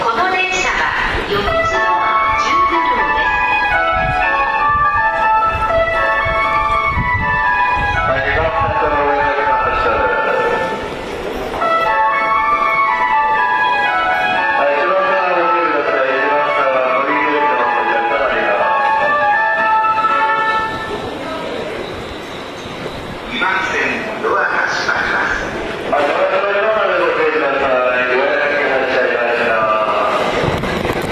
曲名不明 発車メロディなのかと疑いたくなります